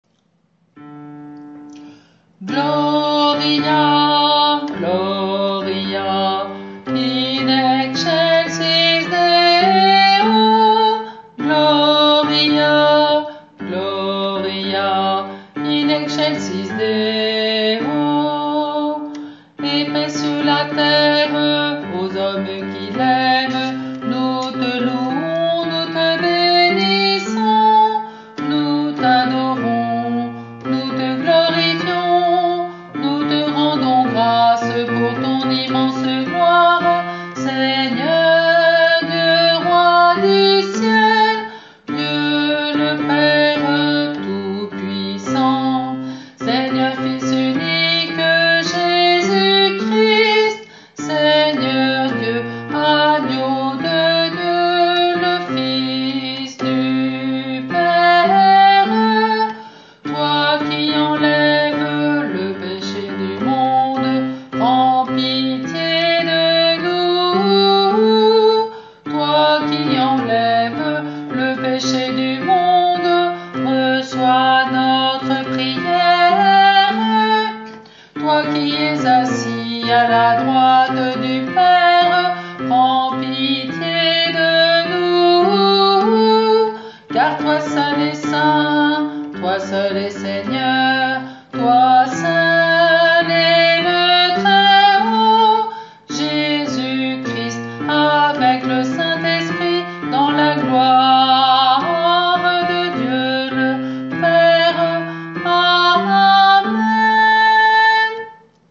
Gloria-de-st-Jean-B.mp3